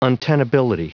Prononciation du mot untenability en anglais (fichier audio)
Prononciation du mot : untenability